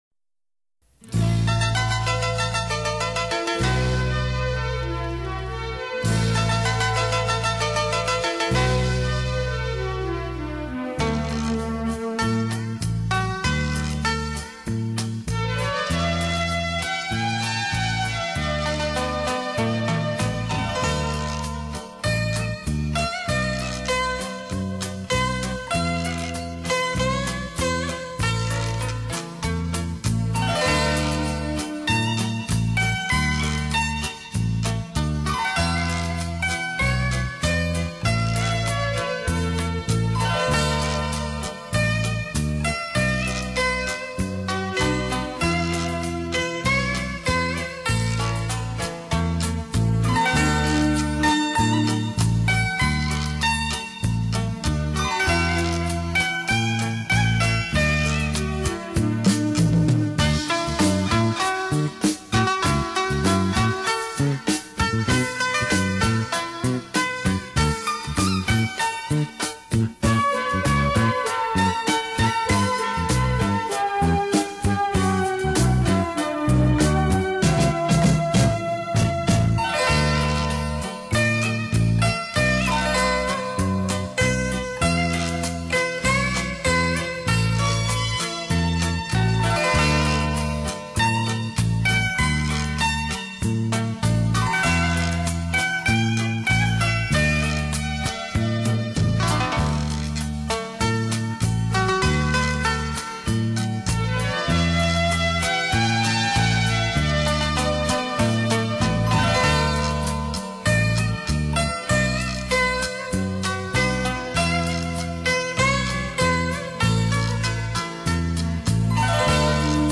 既有传统古筝造诣的妙韵
又有现代气息之风采,让人听了宛如
被清晨的露水洗过了一般，凉净舒畅